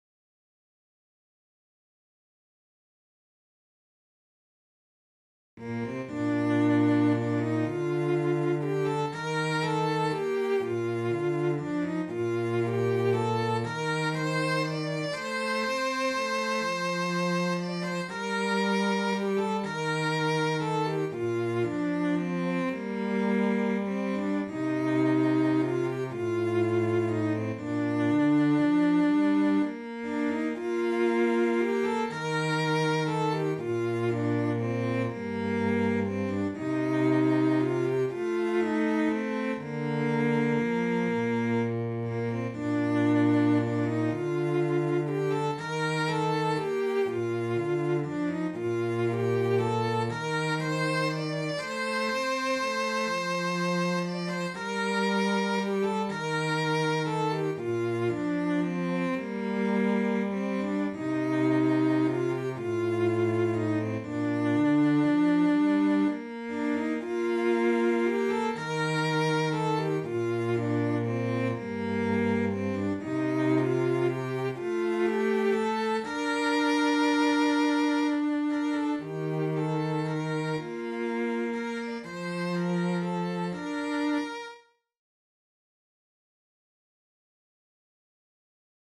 Aika-tuo-ja-vie-sellot.mp3